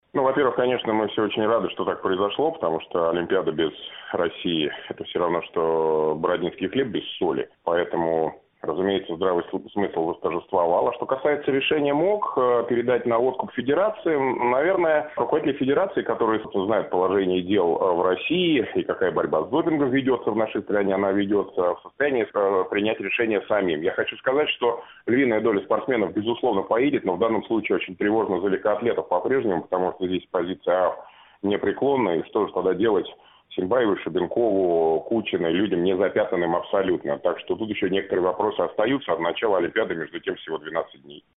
Ведущий канала "Матч-ТВ" Дмитрий Губерниев - о решении МОК не отстранять Россию от Игр в Бразилии